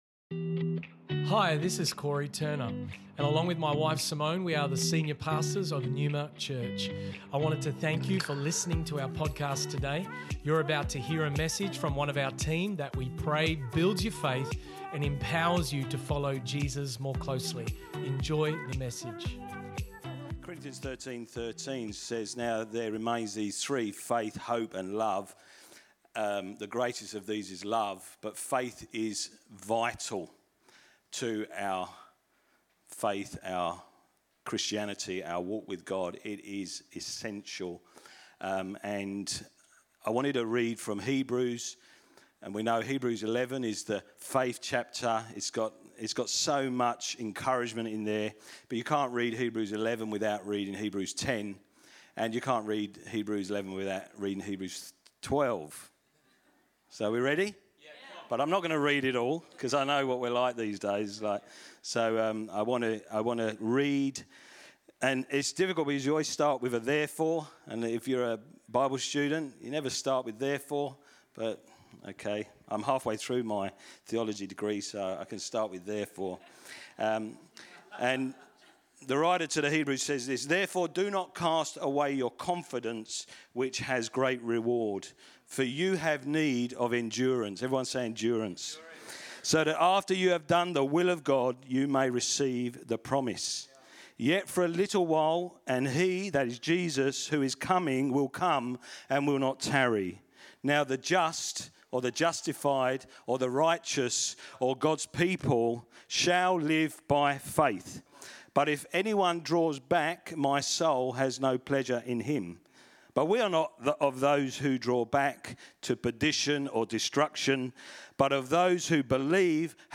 This message was preached on 21 November 2021, at NEUMA Church in Perth.